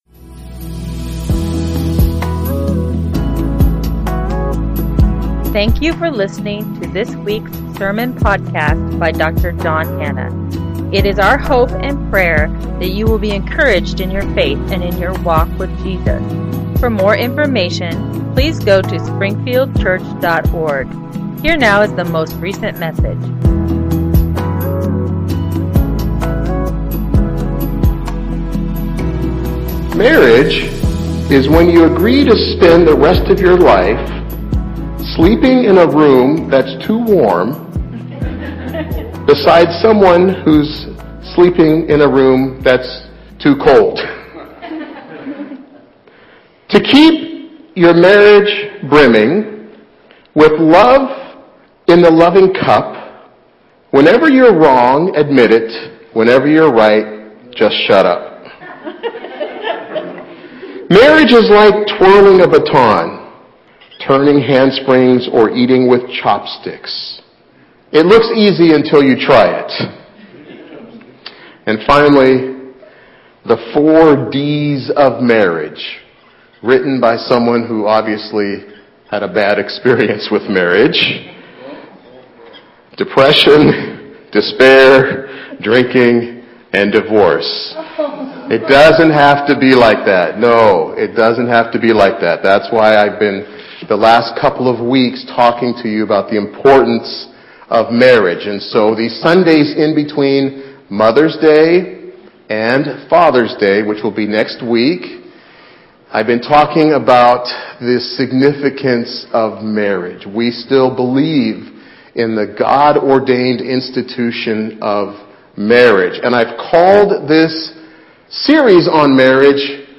As we conclude our series on marriage, we provide some practical and down to earth advice on how to build and sustain a God honoring, relationally fulfilling marriage [note: sermon has been edited to 28 minutes for podcast]